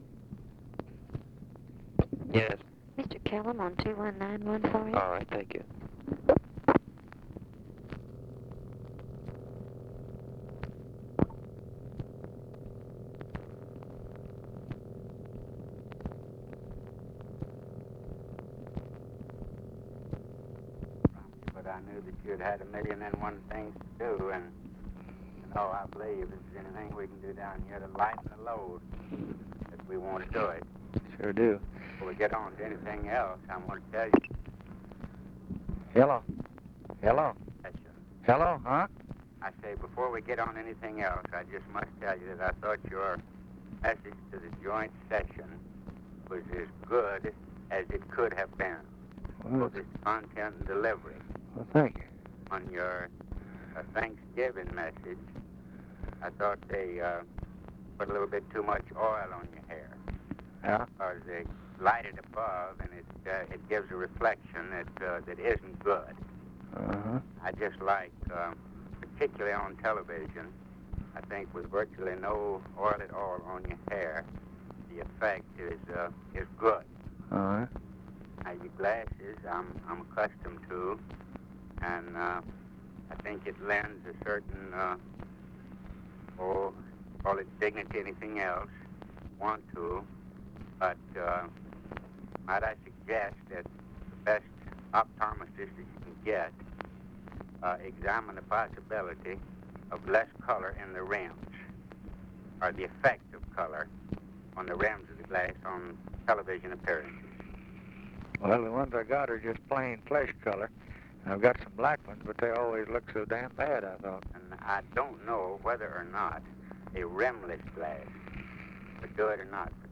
Secret White House Tapes | Lyndon B. Johnson Presidency